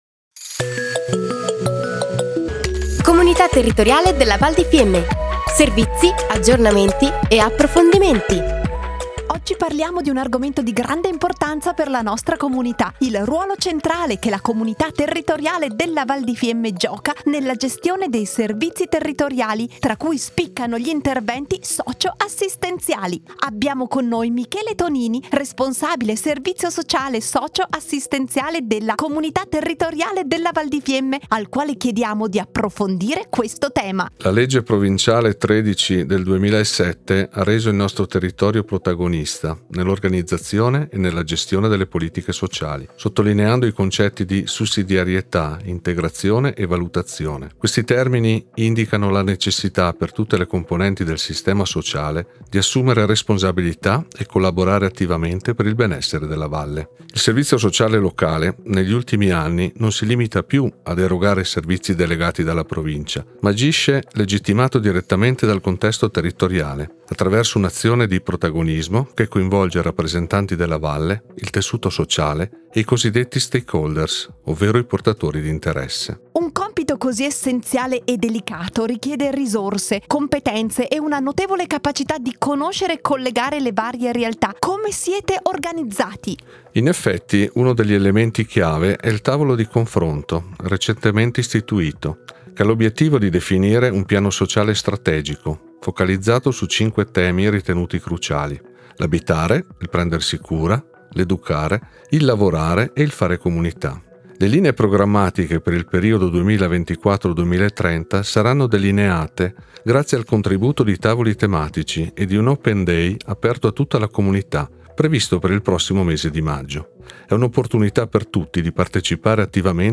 Interviste / La Comunità si presenta tramite Radio Fiemme